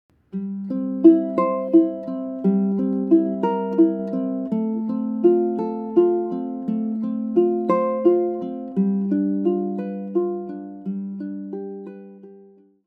Sanoe is a ballad in triple meter and, thus, works well with the P-i-m-a-m-i arpeggio we used in several prior pieces.
Sanoe and P-i-m-a-m-i | First line chords with the P-i-m-a-m-i Arpeggio.
ʻukulele
Sanoe_pimami_demo.mp3